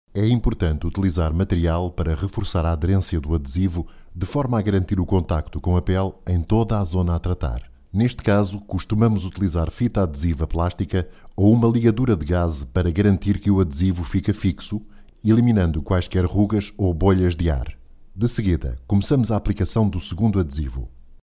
voice over
locutor de Portugal